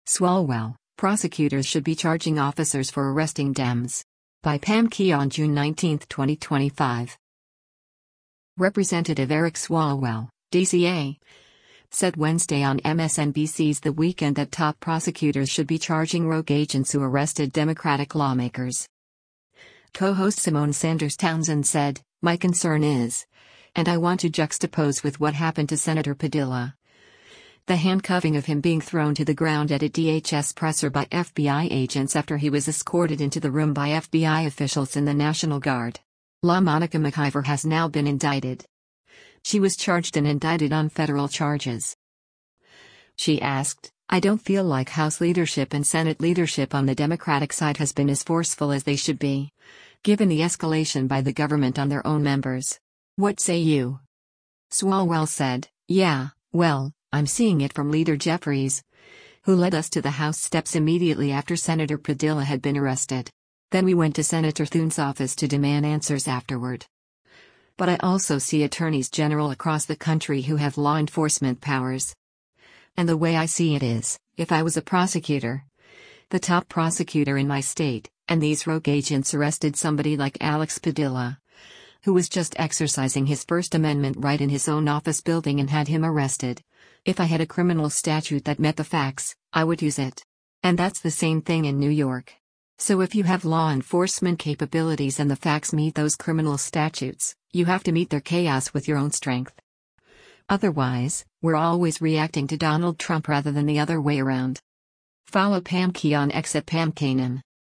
Representative Eric Swalwell (D-CA) said Wednesday on MSNBC’s “The Weekend” that top prosecutors should be charging  “rogue agents” who arrested Democratic lawmakers.